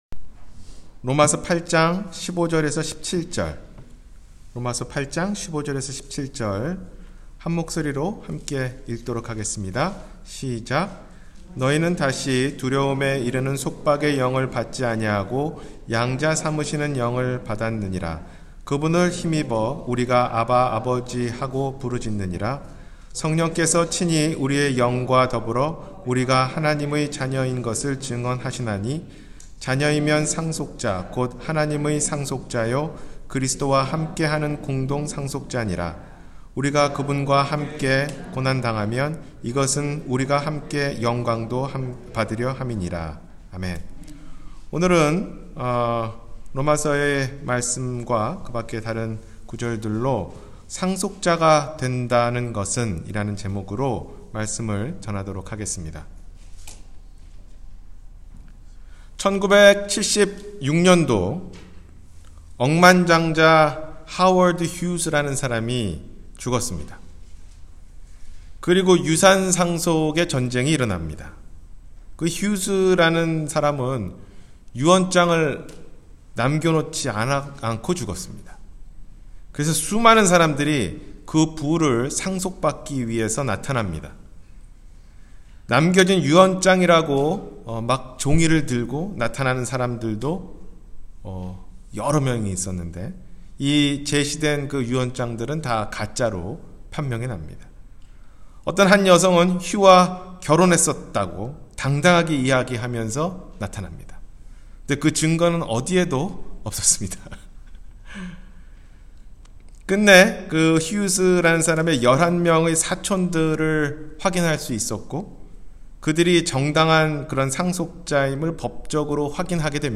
상속자가 된다는 것은 – 주일설교